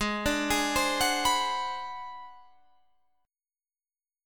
Ab7#9b5 chord